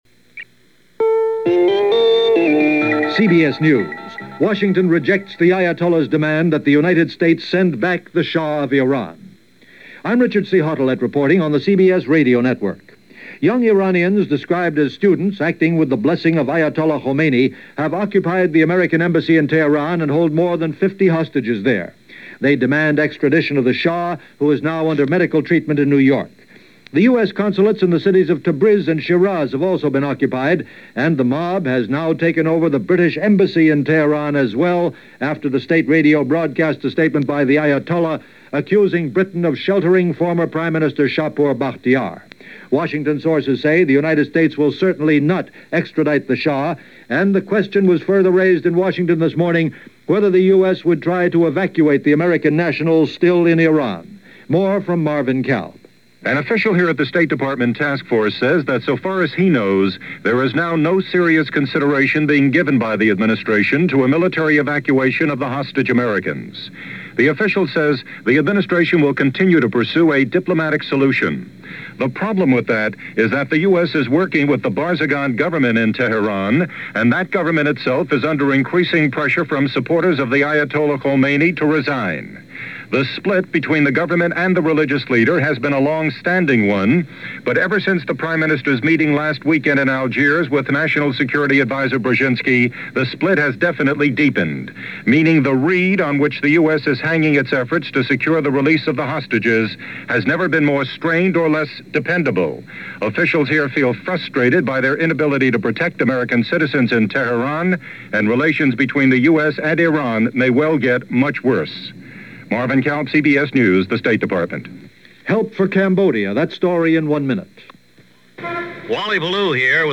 News and bulletins for this day in 1979.